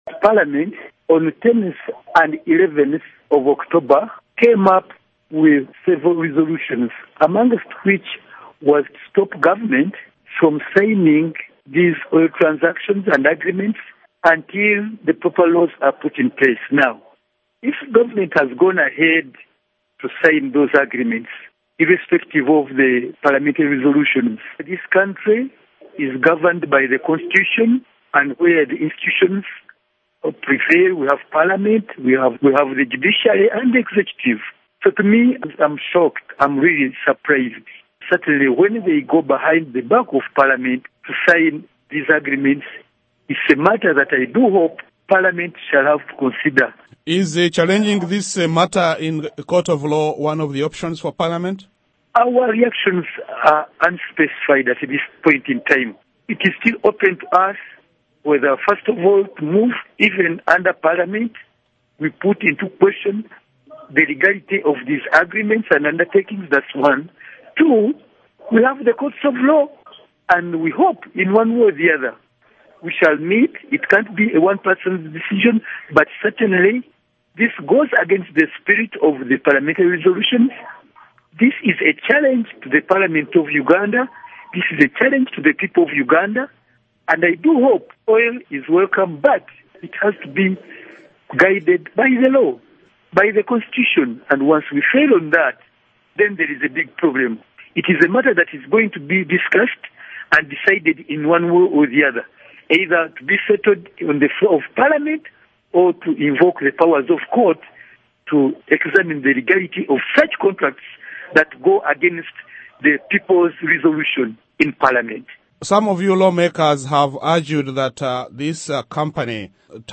Interview with Theodore Ssekikubo